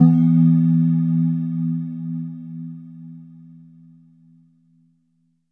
LEAD E2.wav